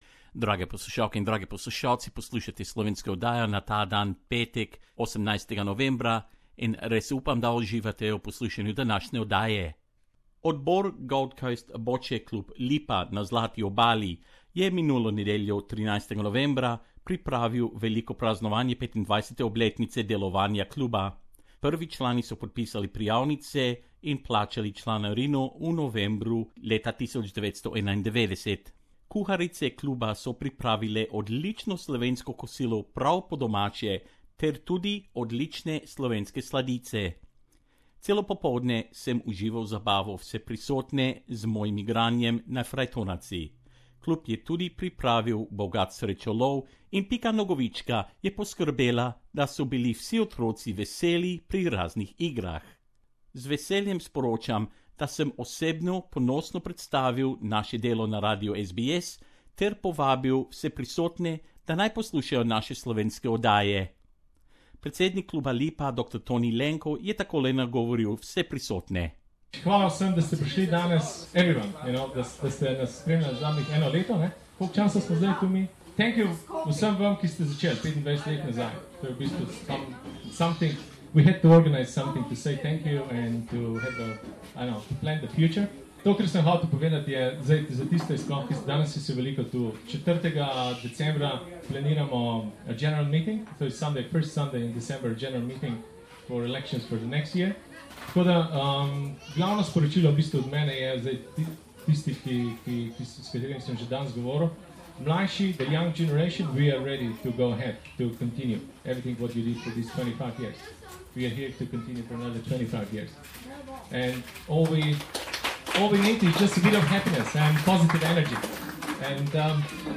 The executive committee of Gold Coast Bocce Club Lipa organised a special function to mark the 25th anniversary of their club.